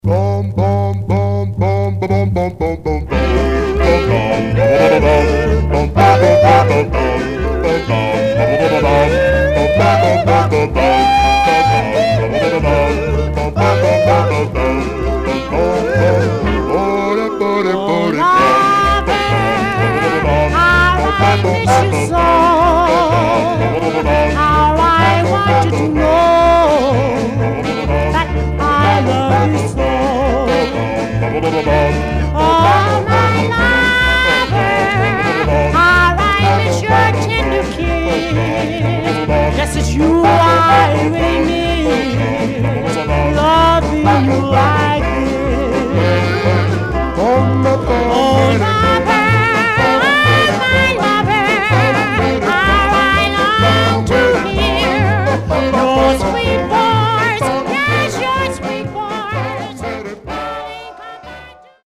Some surface noise/wear
Stereo/mono Mono
Male Black Groups